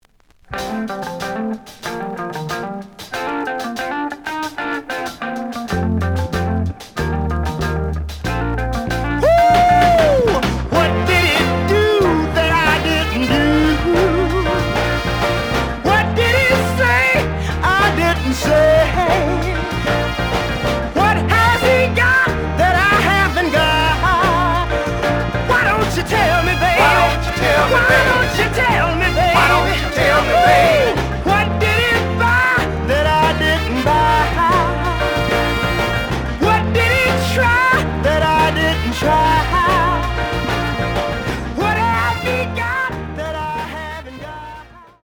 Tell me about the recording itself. The audio sample is recorded from the actual item. Slight sound cracking on both sides.)